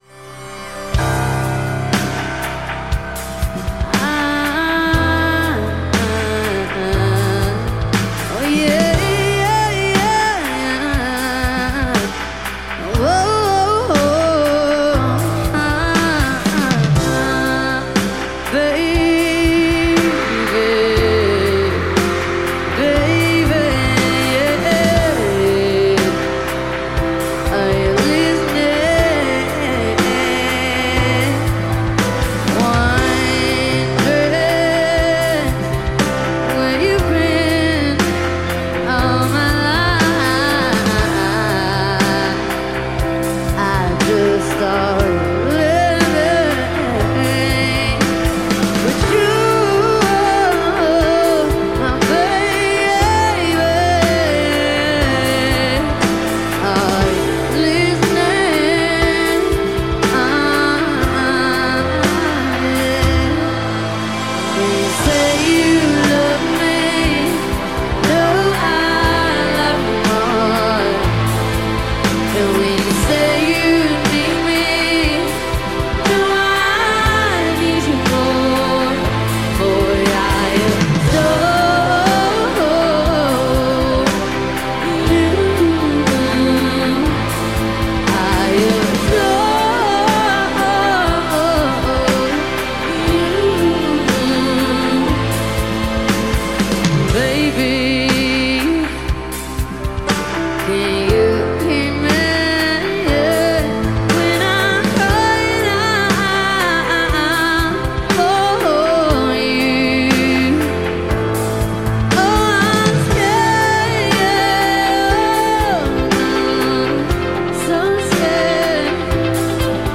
Pop / Synthpop / Queer Pop